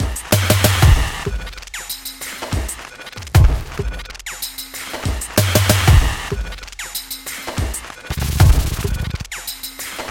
描述：一个带有轻微偏色的节奏的环境节拍。
Tag: 95 bpm Weird Loops Drum Loops 1.70 MB wav Key : Unknown